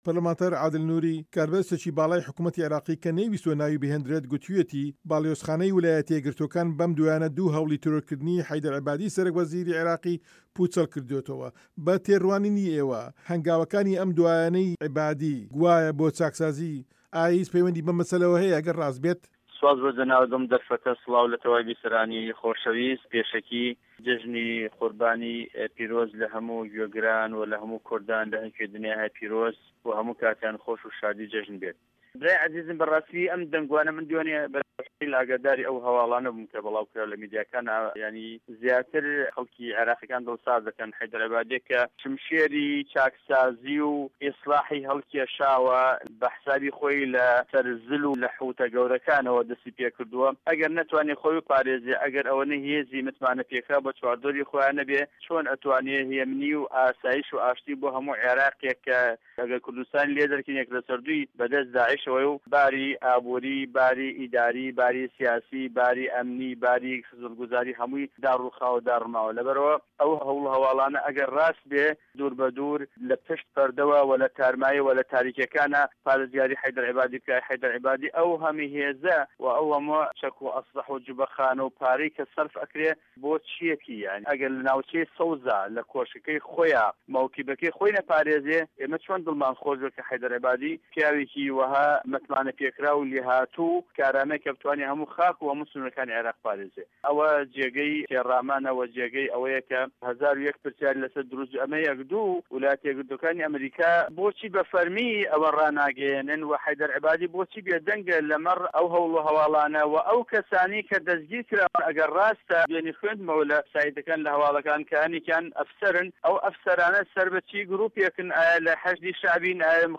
وتووێژ له‌گه‌ڵ عادل نوری